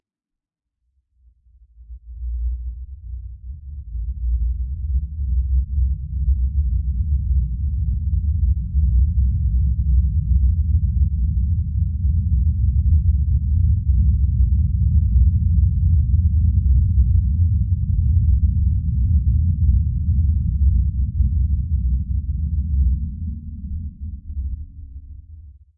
描述：使用带有混响和其他小效果的基本预录音频文件创建的声音。
Tag: 背景声 声景 气氛 环境 背景 测试 隆隆声 氛围 测试 一般噪声 气氛